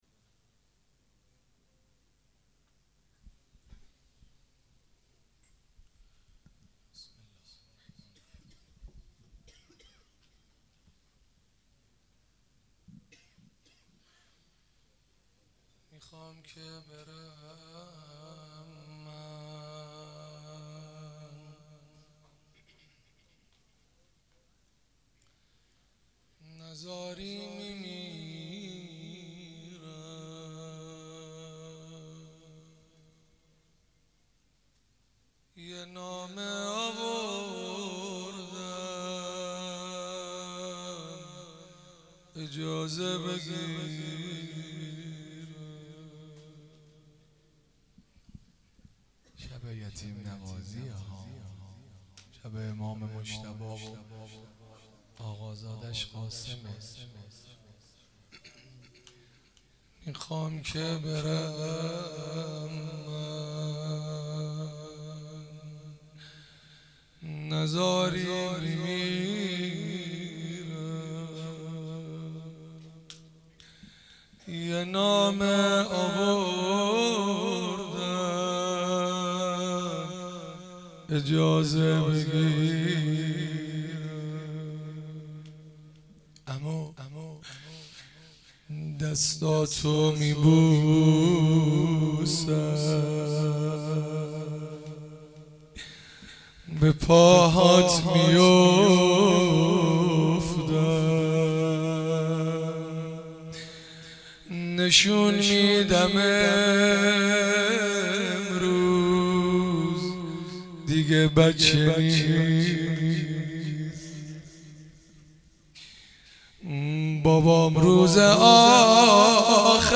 مراسم شب ششم محرم الحرام 97